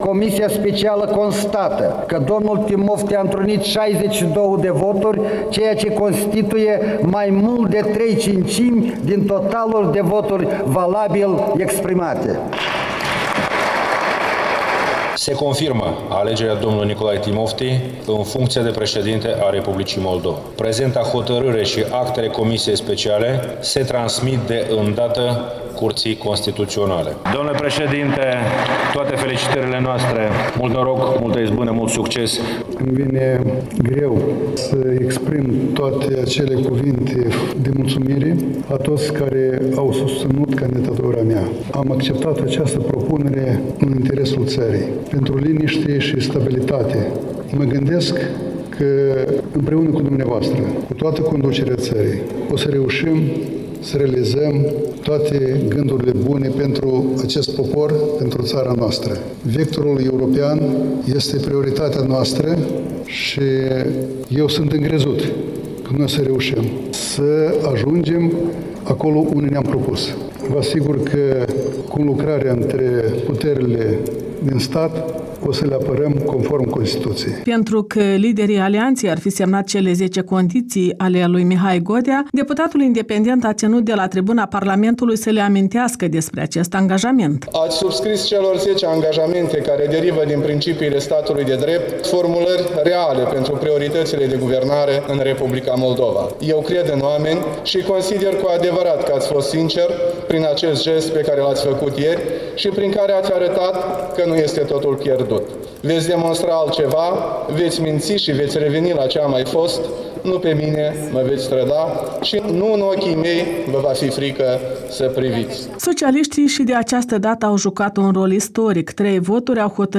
Reportaj din ziua alegerii preşedintelui Moldovei, Nicolae Timofti